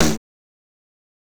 • 2000s Solid Acoustic Snare Sample D# Key 34.wav
Royality free snare single hit tuned to the D# note. Loudest frequency: 1799Hz